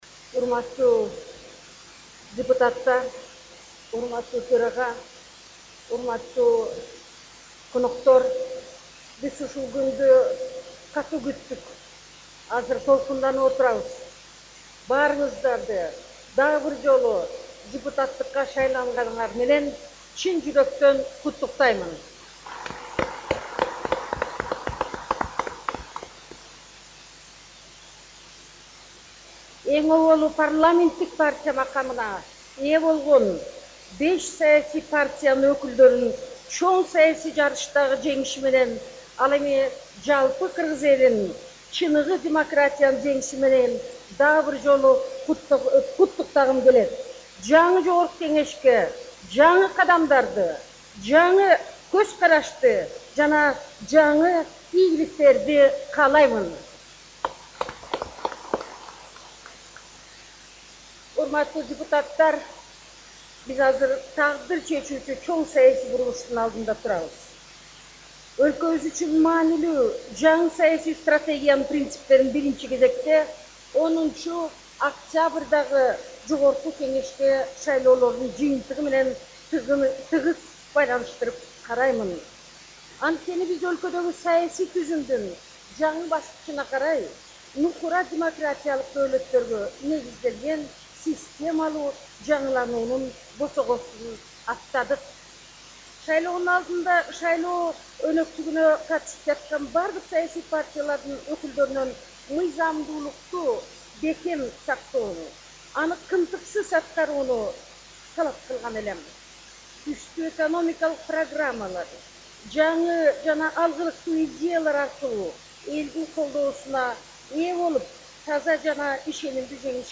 Президенттин парламент жыйынындагы сөзү